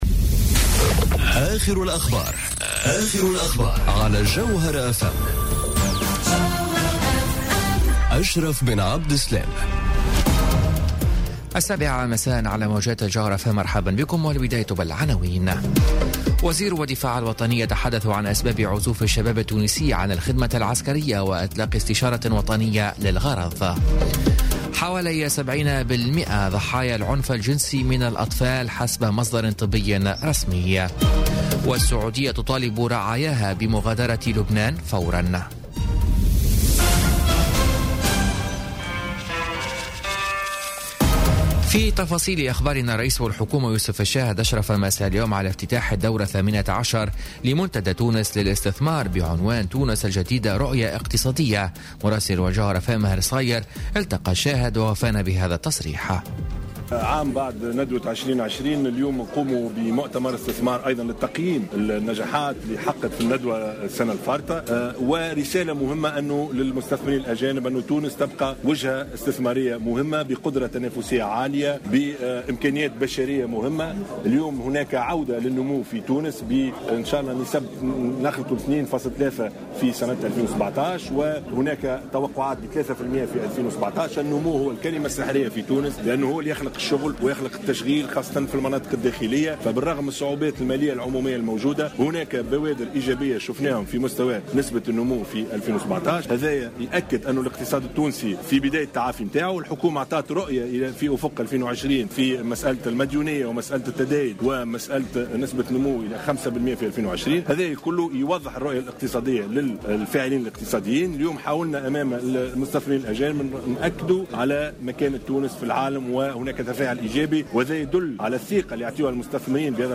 نشرة أخبار السابعة مساء ليوم الخميس 9 نوفمبر 2017